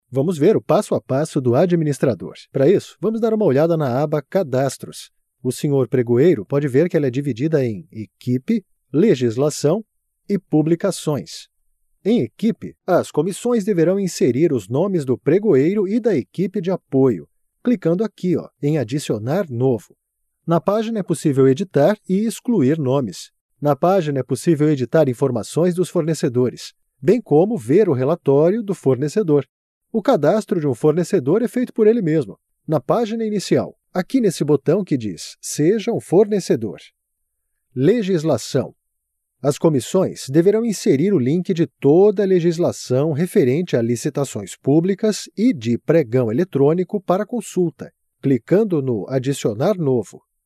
Male
Adult (30-50)
E-Learning
An Educative, Patient, Tender, Sincere, Approachable And Loving Voice.
0130demo_e-learning.mp3